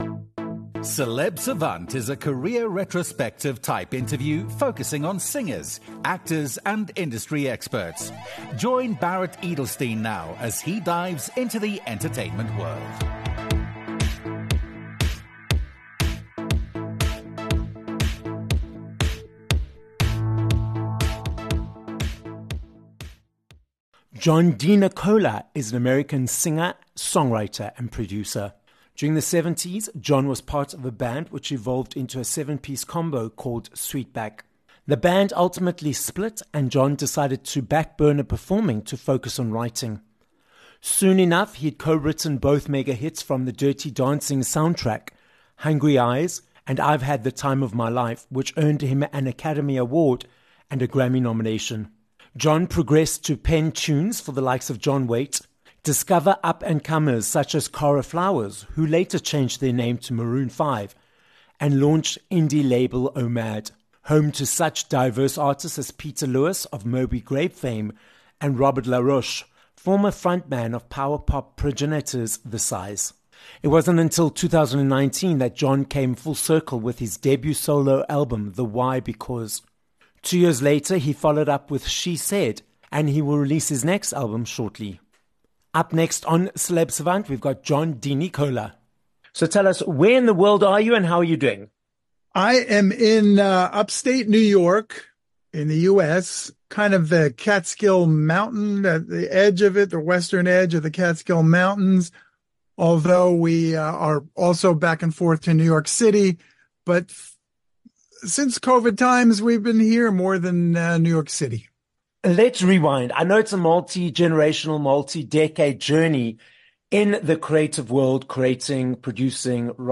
John DeNicola - an American Oscar-winning singer, songwriter and producer - joins us on this episode of Celeb Savant. John dives into his multi-decade, award-winning career that includes composing songs for artists, which saw him win an Oscar for co-writing '(I've Had) The Time of My Life' for the Dirty Dancing soundtrack… moving into his recent solo career and his indie record label, Omad.